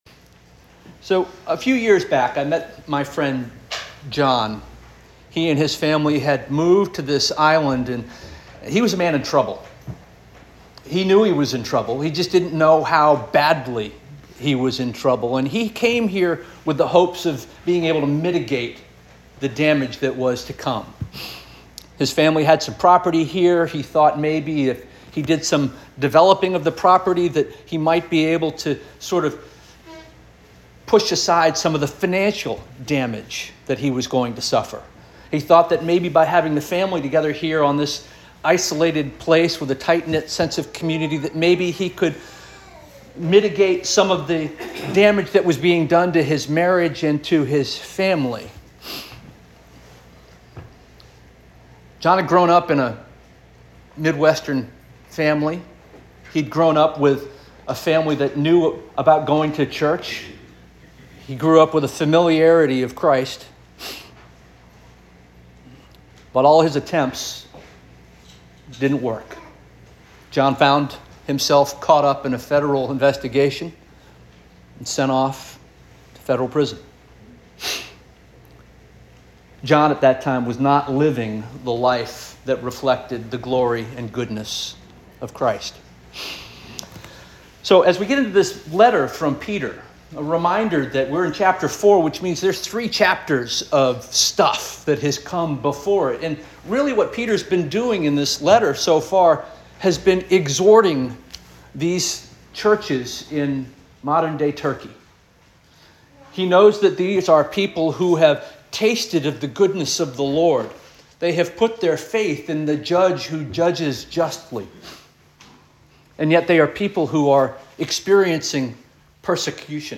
March 23 2025 Sermon